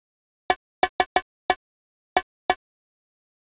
Klassiek voorbeeld van een syncope: op het moment dat een noot verwacht wordt gebeurt er niets.
syncope.wav